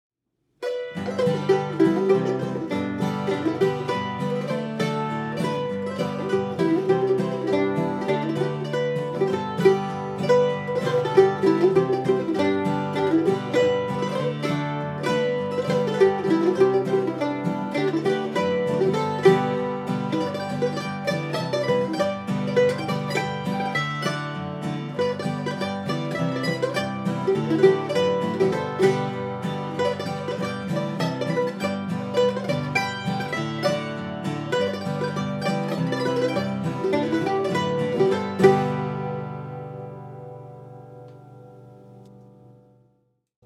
SHOVE THE PIG'S FOOT | MANDOLIN
ShovePigsFootDuet.mp3